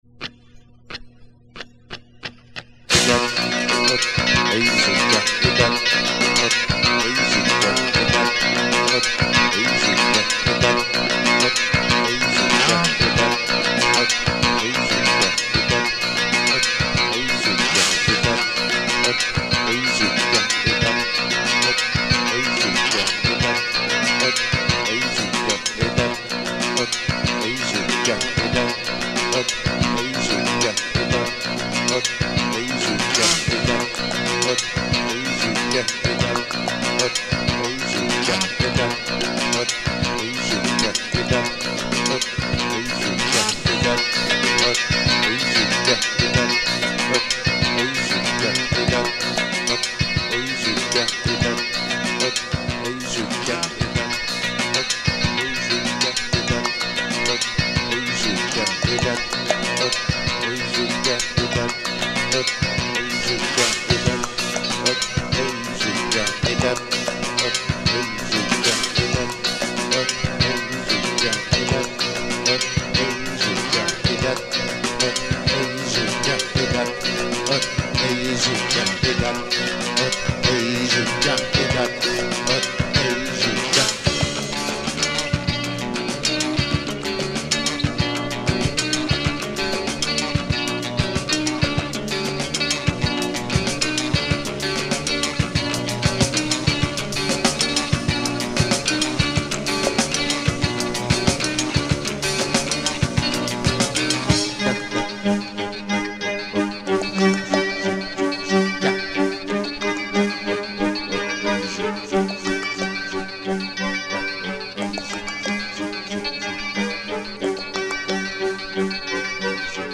českého alternativního rocku